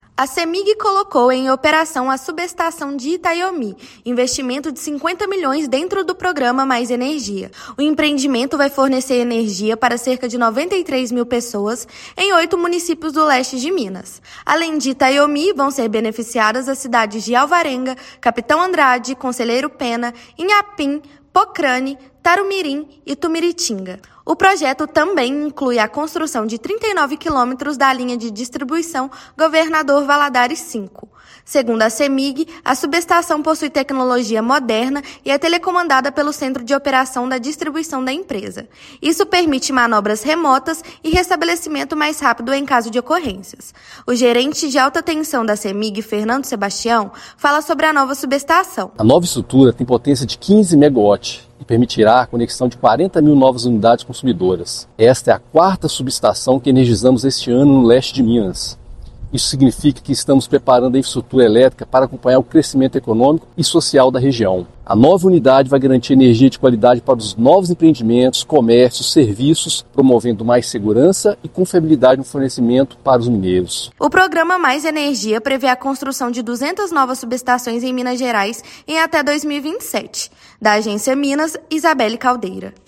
Empreendimento, que faz parte do Programa Mais Energia, reforça infraestrutura elétrica e impulsiona desenvolvimento regional. Ouça matéria de rádio.